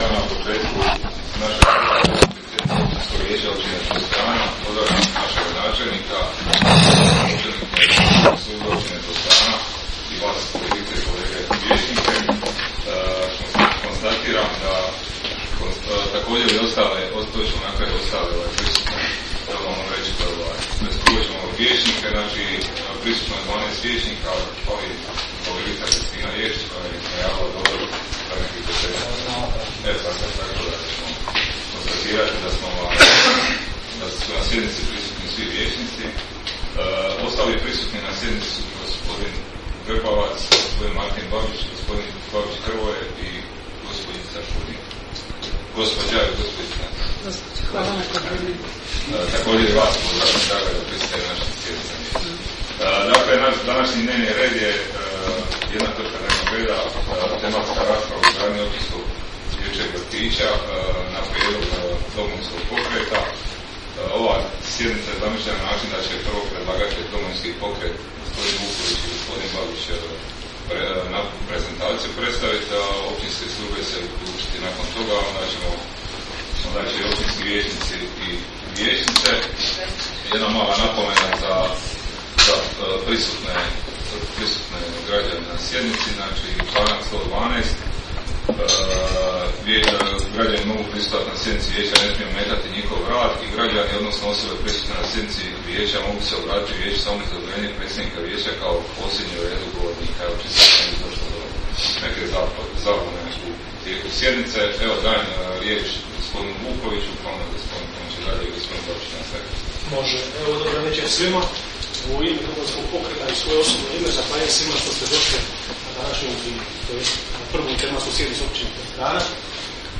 koja će se održati dana 03. ožujka (četvrtak) 2022. godine u 19,00 sati u Sali za sastanke Općine Podstrana,